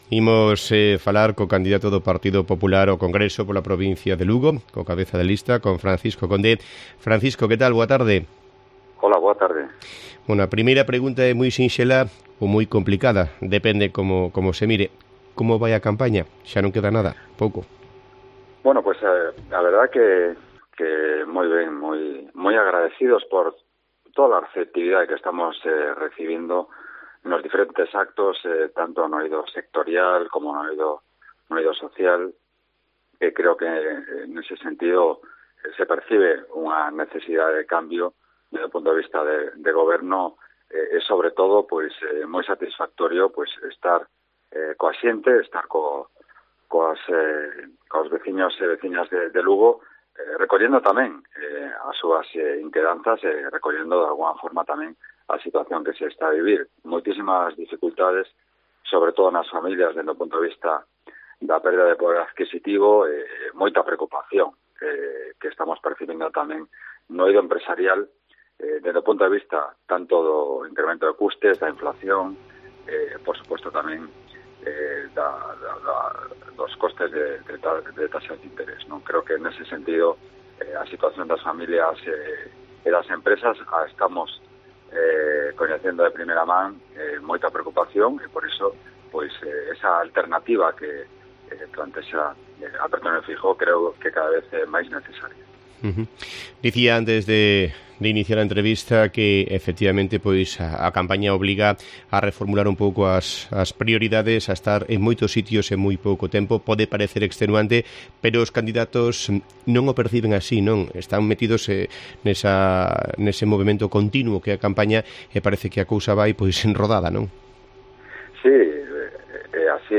ENTREVISTA A FRANCISCO CONDE